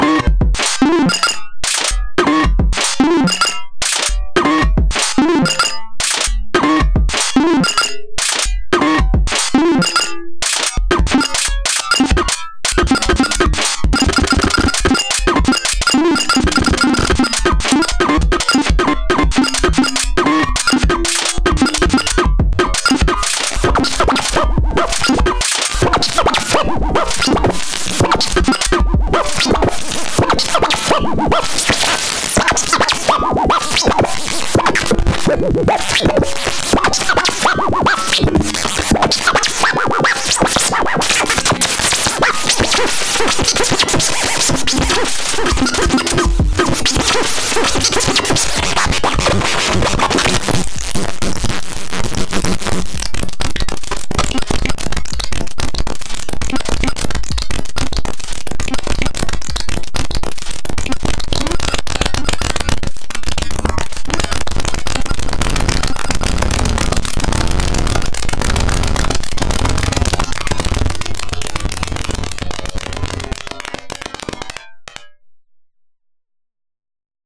Early stage of a live resampling patch à la octatrack, with slices (8 slices here), record trigs, play trigs and randomization of the slices.
A fun little audio example of a live resampled mangled loop made with this patch coupled with a patch with array modules :